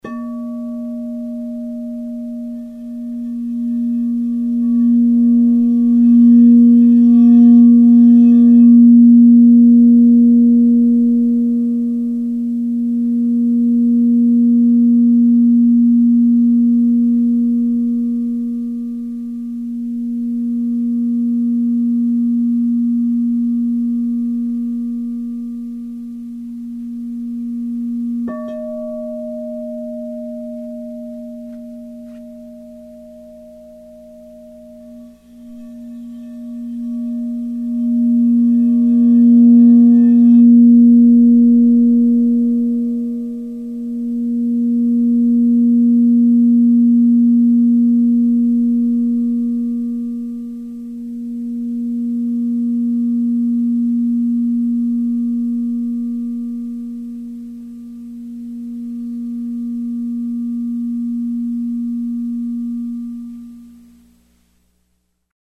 Singing Bowls
The internal spiral structure and the external rounded form of Crystal Singing Bowls produce a non linear, multidirectional Sound.
This process makes the bowls strong and incredibly pure in tone.